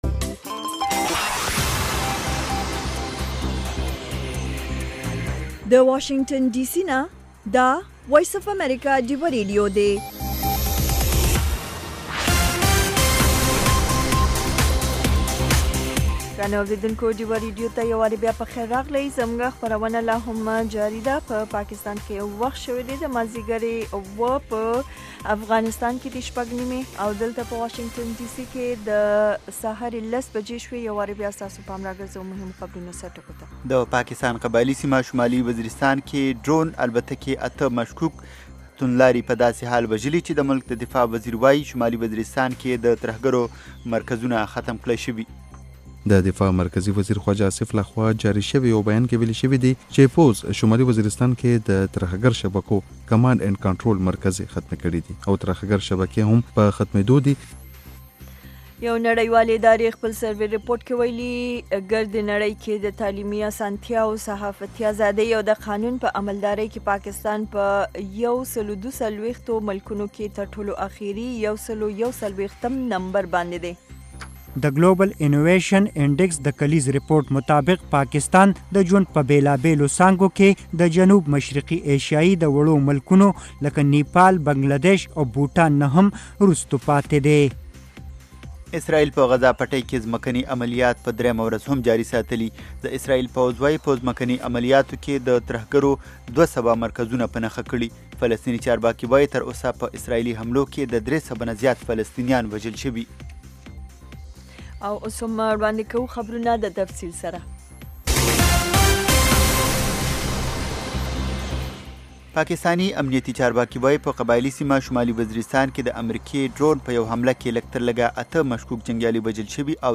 دې یو ساعته پروگرام کې تاسو خبرونه او د هغې وروسته، په یو شمېر نړیوالو او سیمه ایزو موضوگانو د میلمنو نه پوښتنې کولی شۍ.